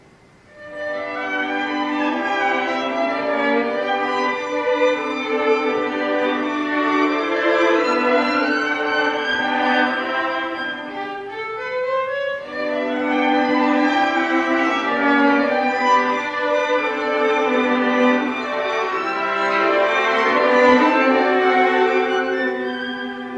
네 번째 변주곡서정적. 두 번째 바이올린과 첼로가 세잇단음표로 된 긴 바이올린 선 아래에서 멜로디를 연주. G장조로 된 유일한 변주곡